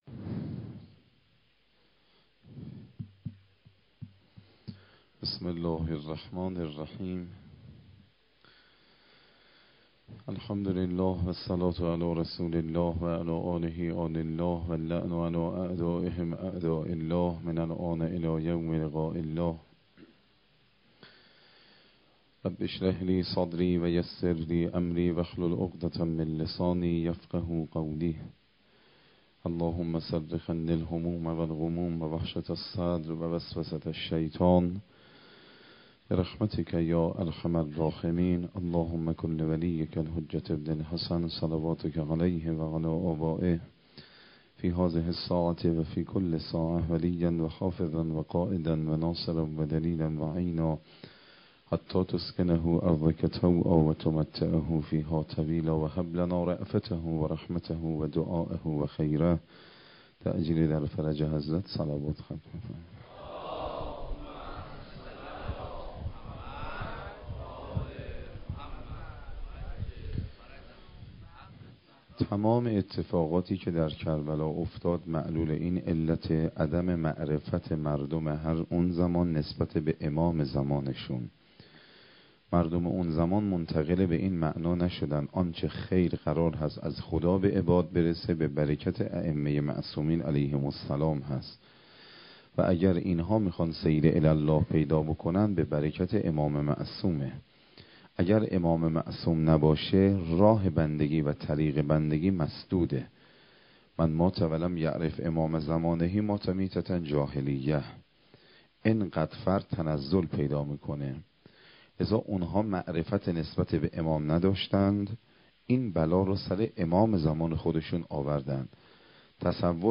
شب ششم محرم 95_سخنرانی_هیئت الزهرا سلام الله علیها